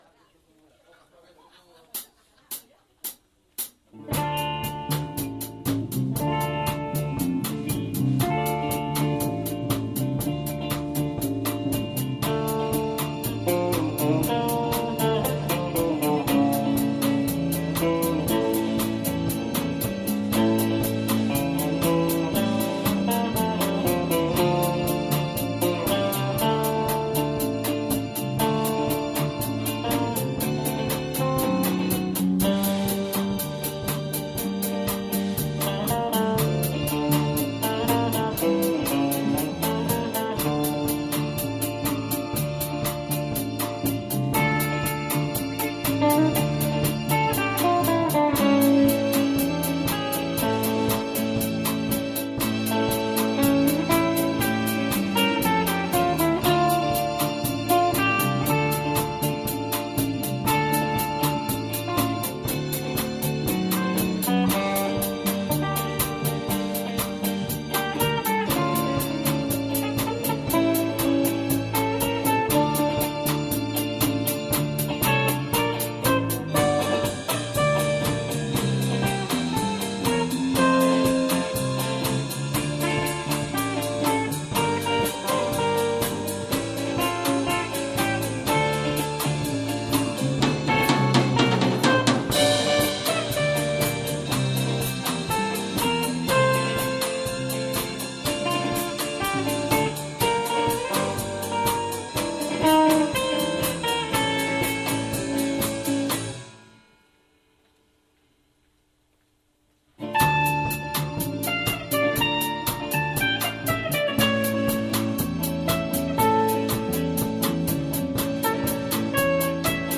2019 Spring Live-4 | The Arou Can